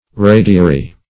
radiary.mp3